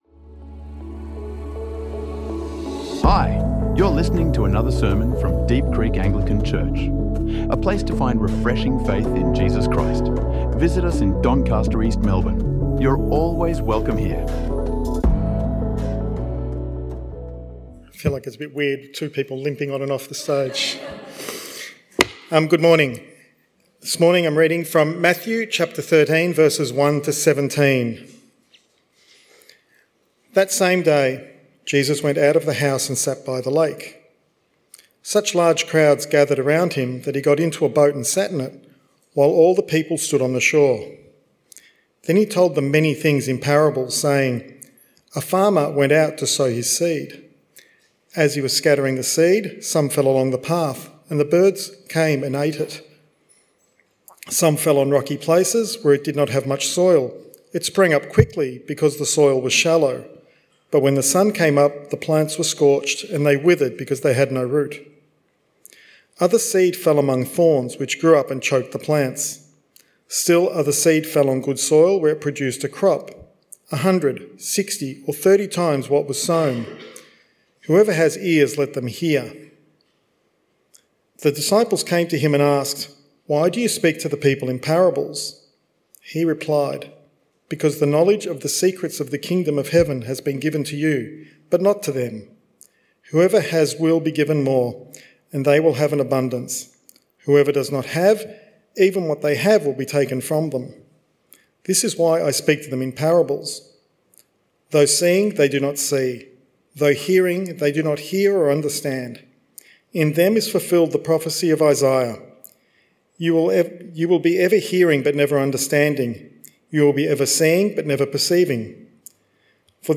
Explore how Jesus’ parables speak to our mental health, stress, and relationships in this honest and hope-filled sermon on thorns and tests.